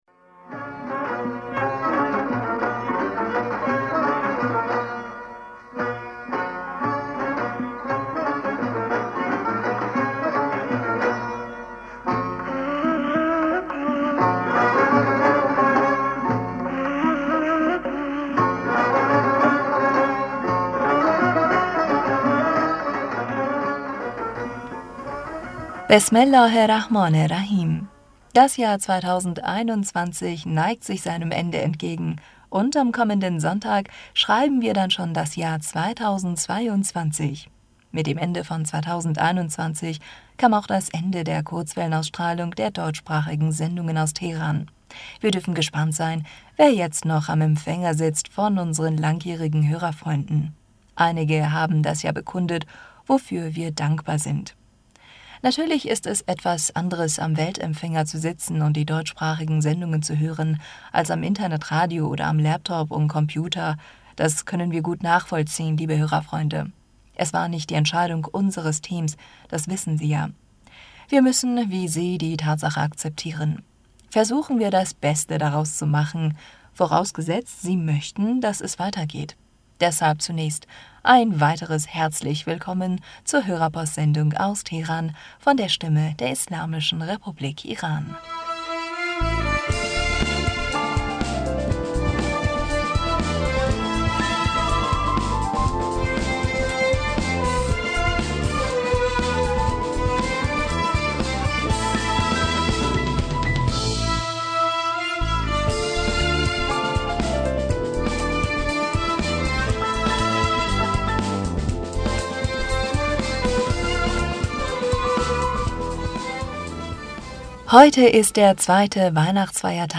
Hörerpostsendung am 26. Dezember 2021 Bismillaher rahmaner rahim - Das Jahr 2021 neigt sich seinem Ende entgegen und am kommenden Sonntag schreiben wir da...